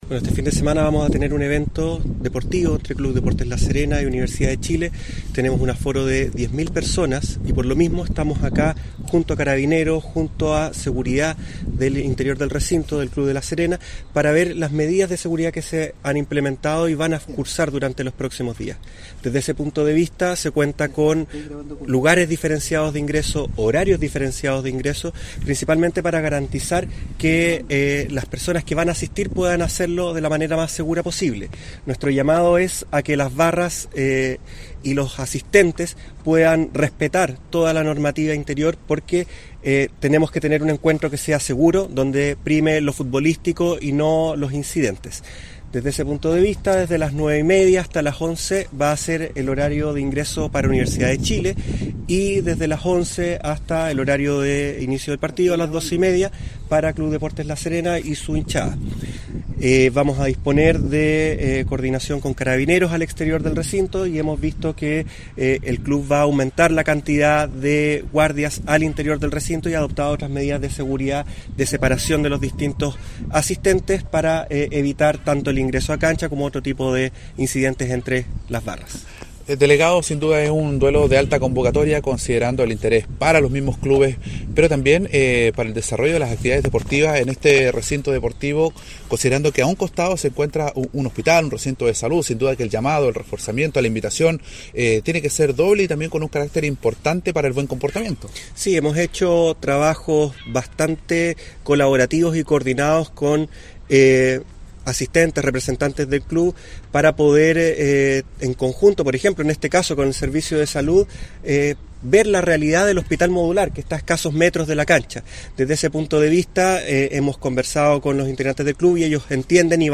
AUDIO : Delegado Presidencial Ruben Quezada
ESTADIO-Delegado-Presidencial-Ruben-Quezada.mp3